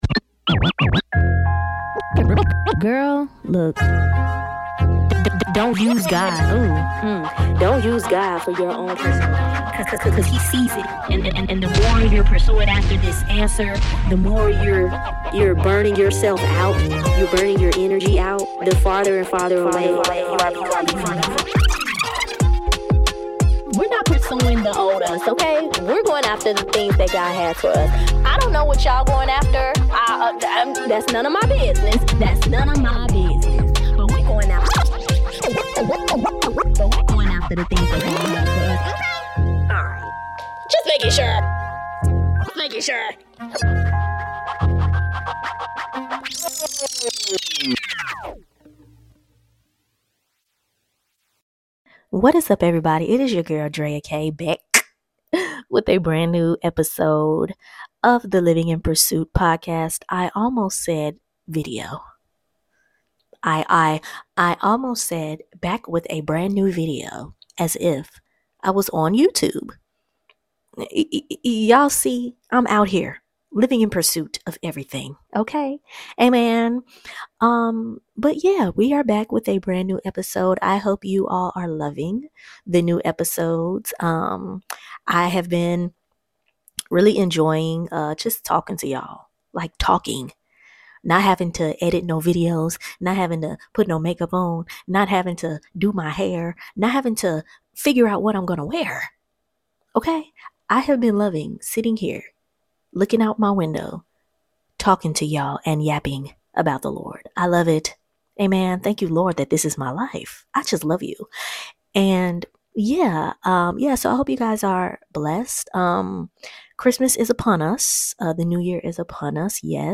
Join me for an uplifting conversation